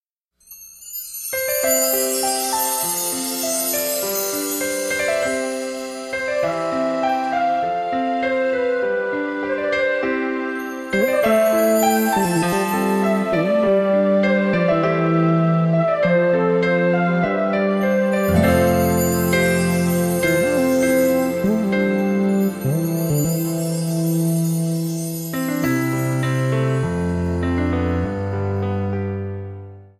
Backing track files: 1990s (2737)
Buy With Backing Vocals.